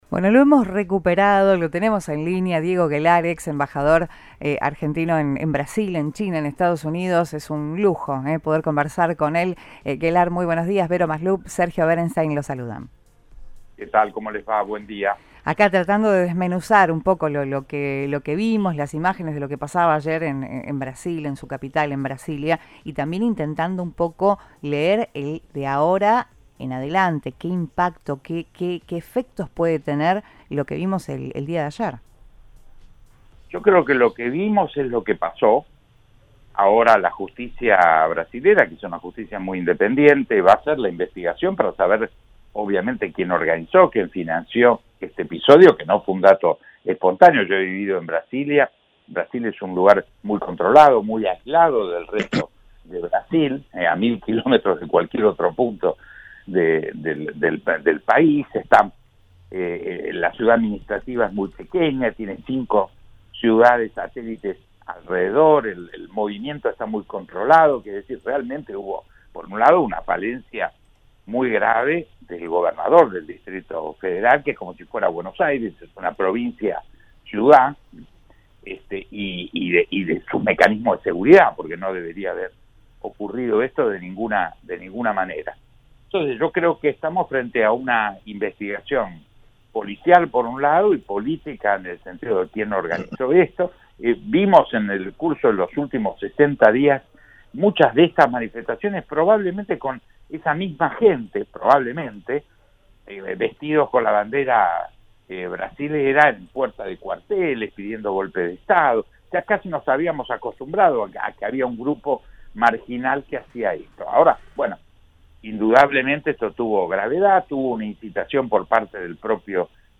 El exembajador argentino en Brasil, Diego Guelar, analizó los violentos episodios que tuvieron lugar este domingo en Brasilia y motivaron una acción extraordinaria de parte del presidente Lula.